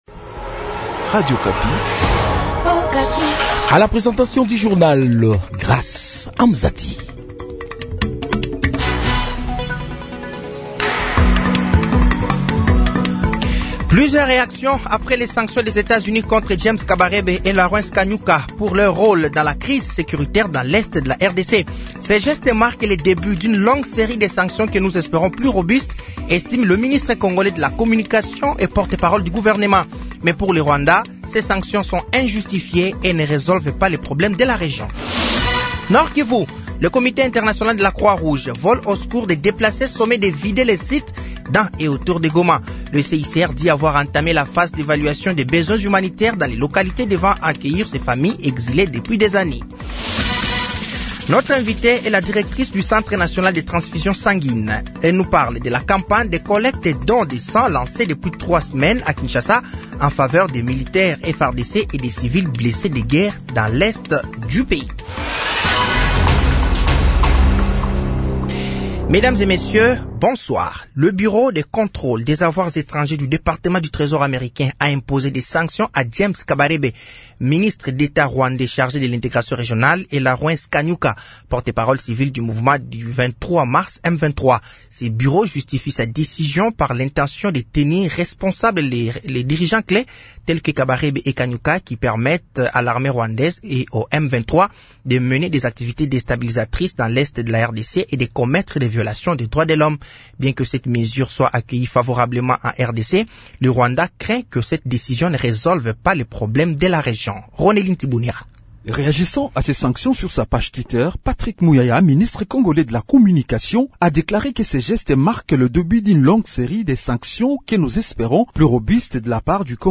Journal français de 18h de ce vendredi 21 février 2025